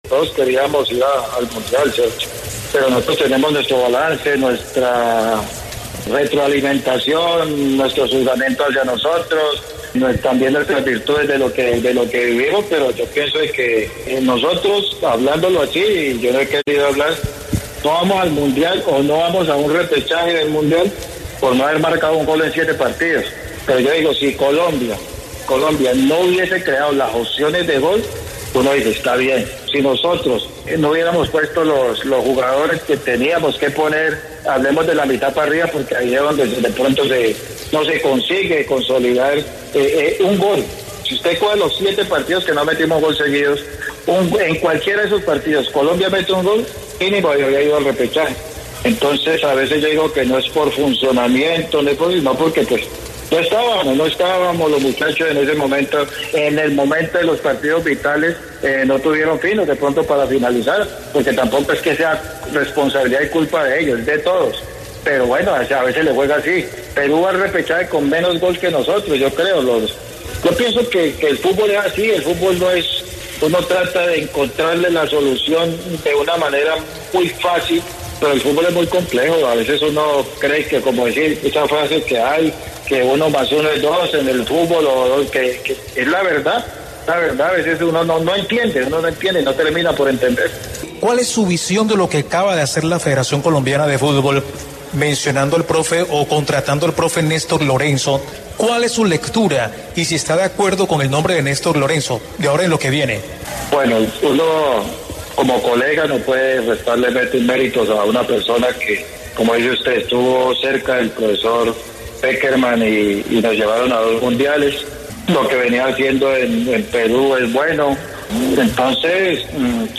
“No es por funcionamiento, nosotros no estábamos, si hubiéramos metido un gol en esos siete partidos que no marcamos, hubiéramos ido al repechaje (...) Perú fue al repechaje con menos goles que nosotros, no es fácil de explicar”, agrego el entrenador en diálogo con el Carrusel Deportivo de Caracol Radio.